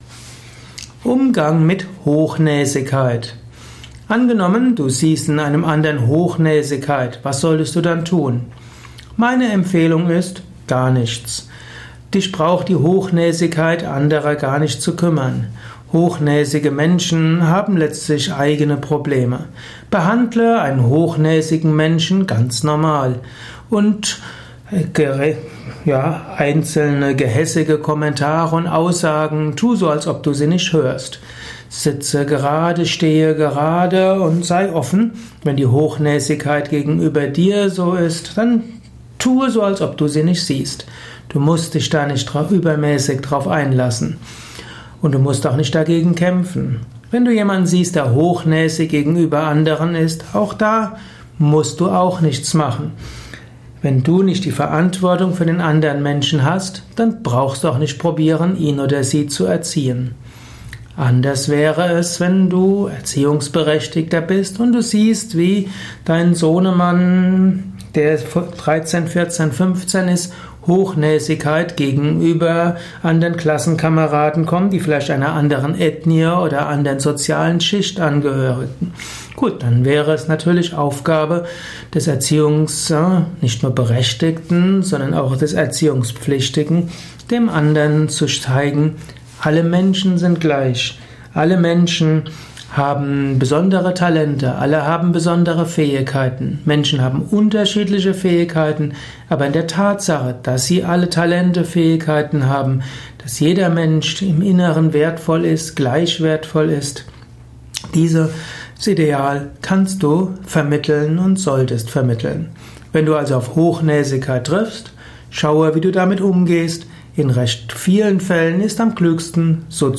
Inspirierender Abhandlung zum Themenkreis Hochnäsigkeit bei anderen. Einige Informationen zum Thema Hochnäsigkeit in einem besonderen Spontan-Audiovortrag.